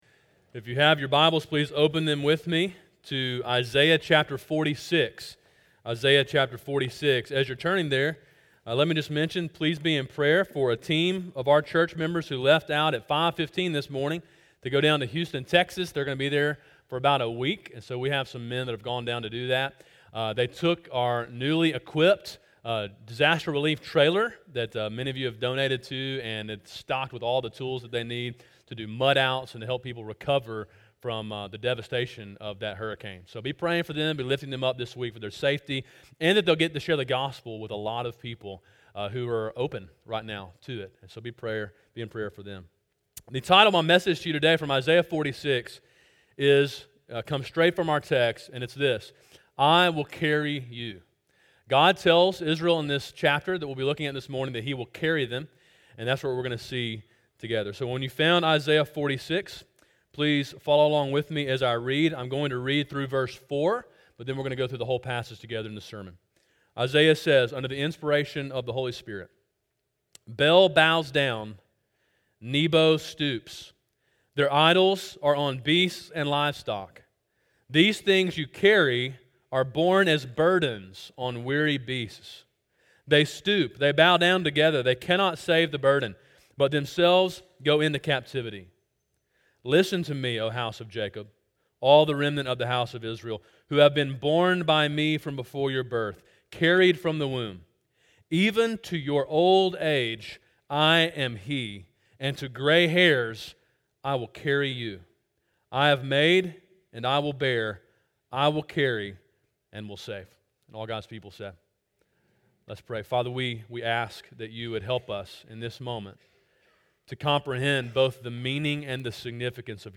Sermon: “I Will Carry You” (Isaiah 46)
sermon9-17-17.mp3